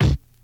Kick (Family Business).wav